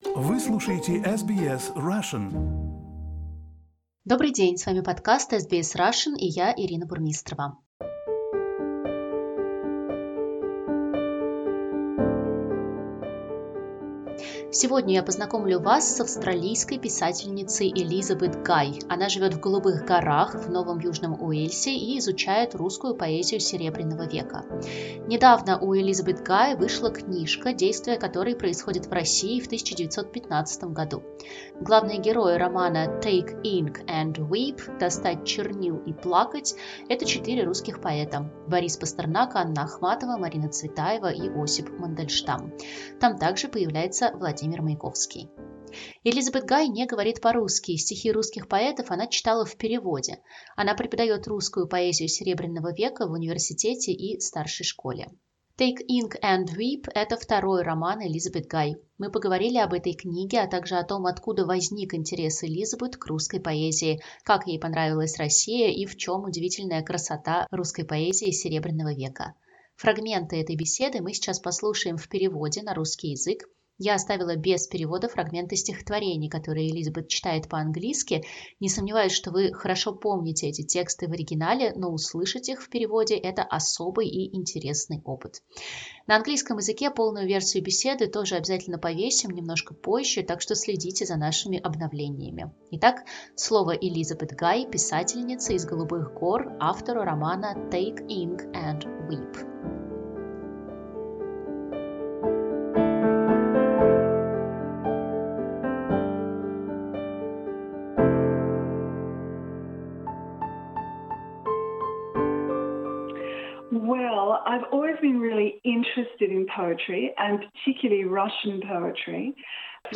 В подкасте звучат стихи поэтов Серебряного века в переводе на английский язык.